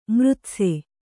♪ mřtse